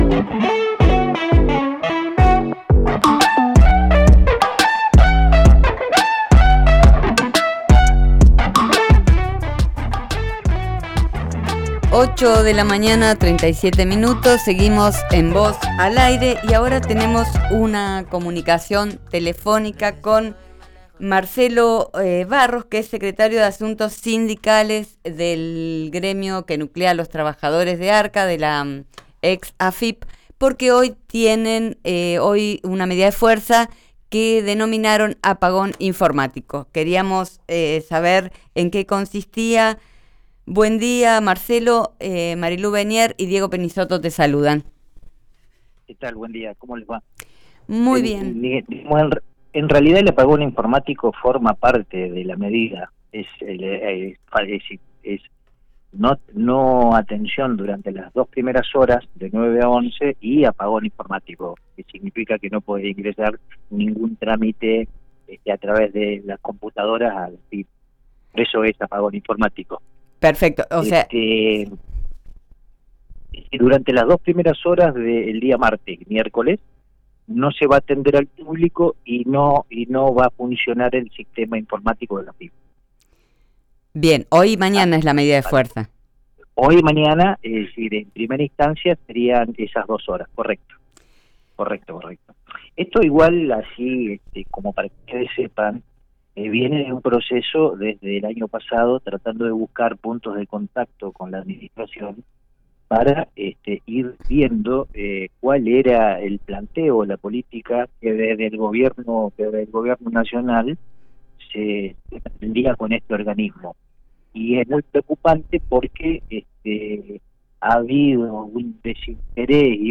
«No hay atención al público los martes y miércoles ,de 9 a 11 en toda la dirección regional, es decir Viedma, Roca, Neuquén, Zapala, y Bariloche», indicó en diálogo con Vos al aire.